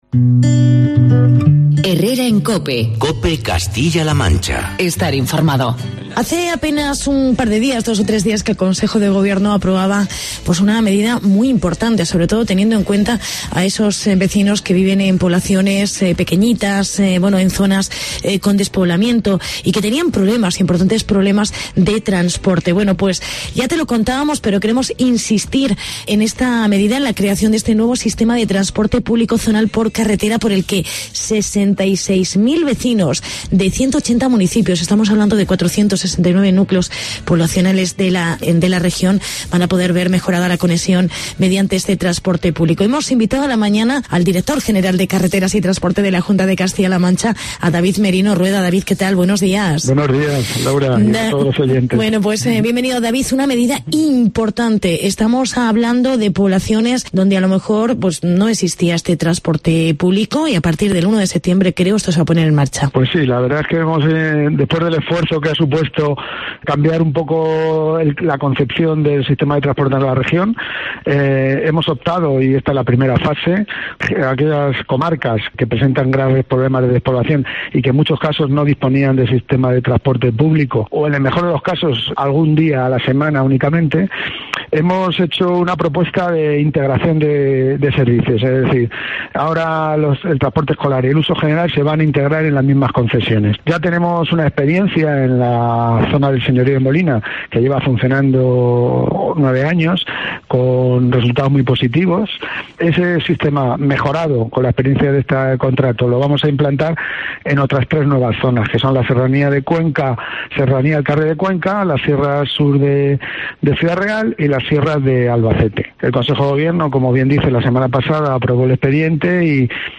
Entrevista con el Dtor. General de Carreteras: David Merino